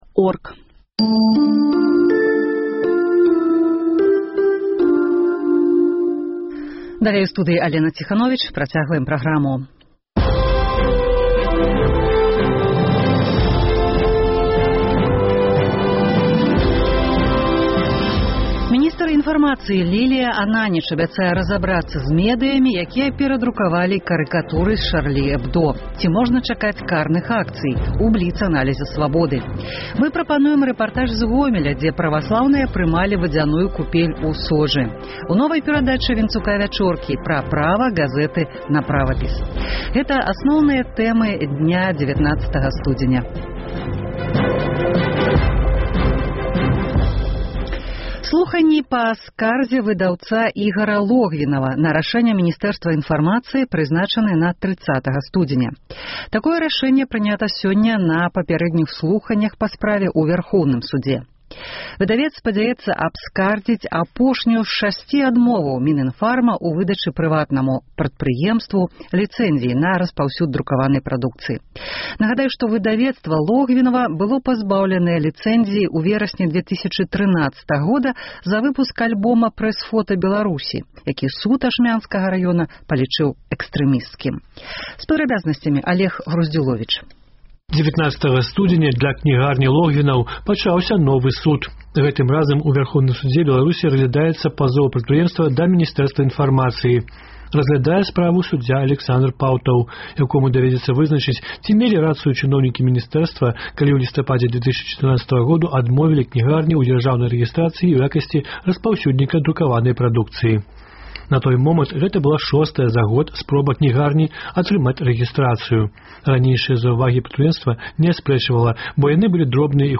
Ці можна чакаць карных акцый? – у бліц-аналізе Свабоды. Рэпартаж з Гомеля, дзе праваслаўныя прымалі вадзяную купель у Сожы.